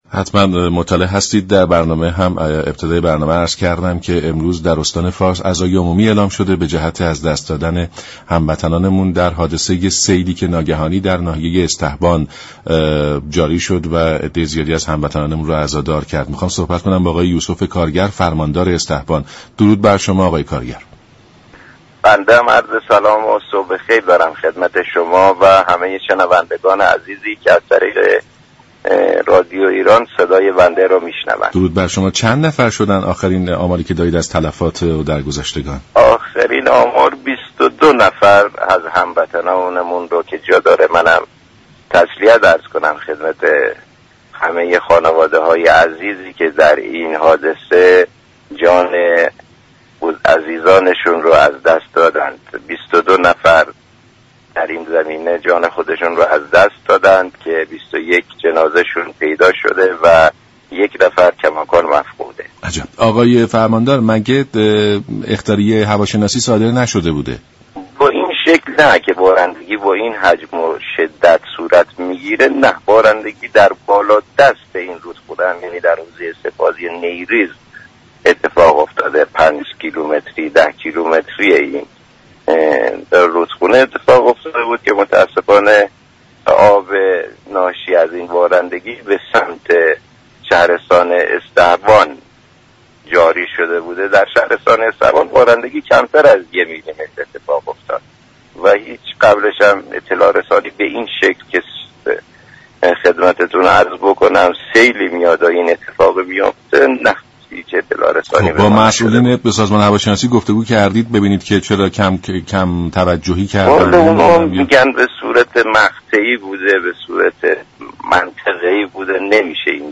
به گزارش شبكه رادیویی ایران، یوسف كارگر فرماندار استهبان در برنامه «سلام صبح بخیر» رادیو ایران سیل اخیر در استهبان و آمار تلفات پرداخت و گفت:در سیل اخیر استهبان 22 نفر از هموطنان جان خود را از دست داده اند از این میزان پیكر بی جان یك نفر هنوز شناسایی نشده است.